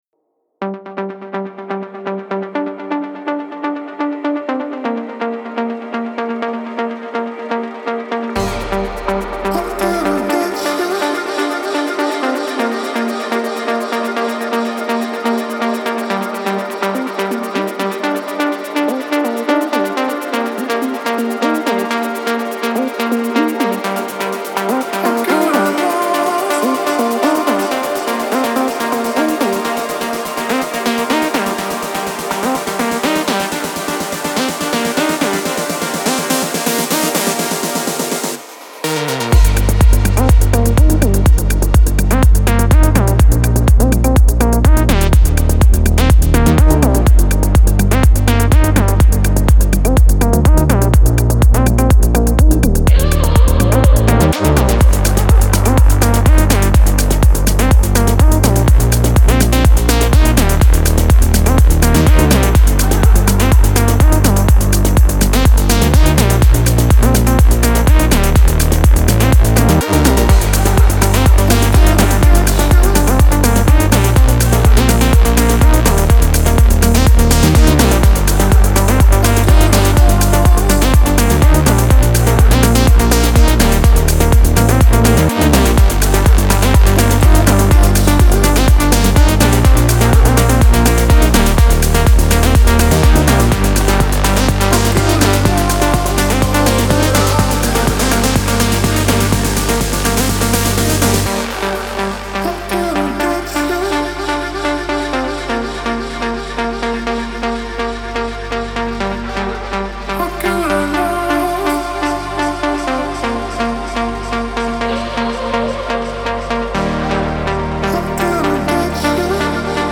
پر‌انرژی
هاوس